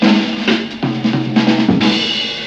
JAZZ BREAK12.wav